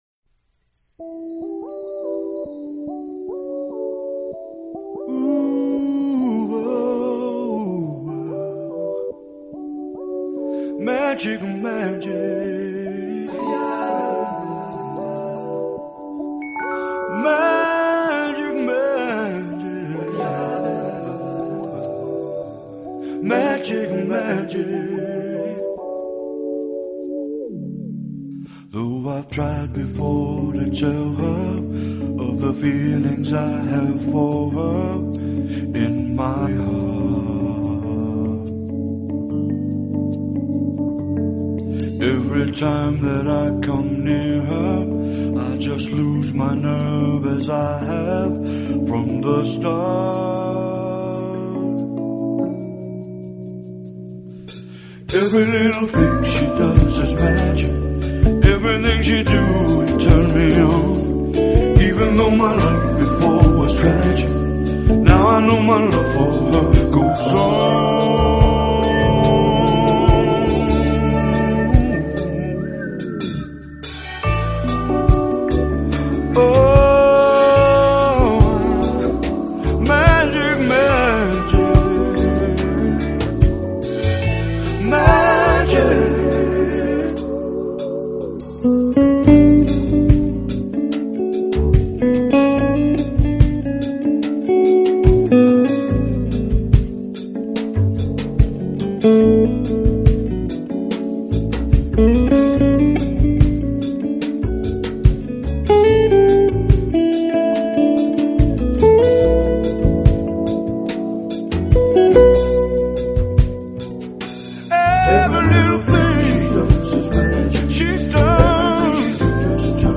Style: Jazz